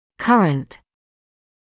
[‘kʌrənt][‘карэнт]современный, текущий